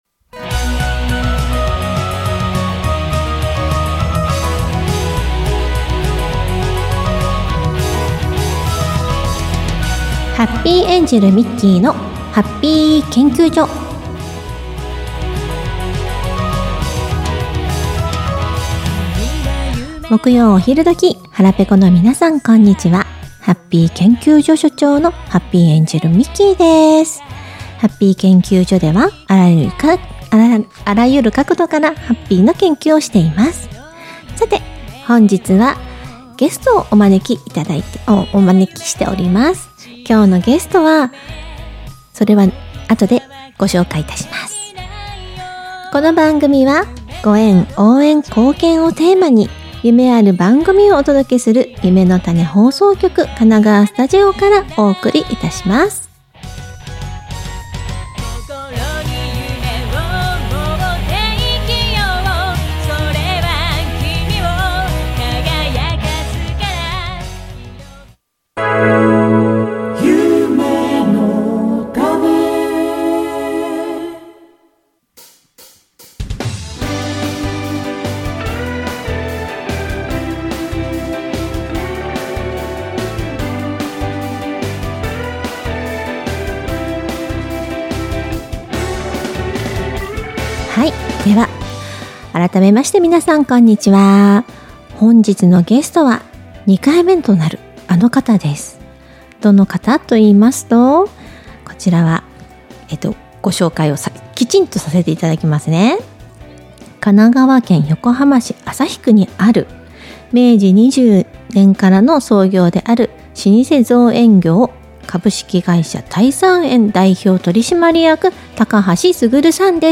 インターネットラジオ アーカイブ放送(2023年10月8日分)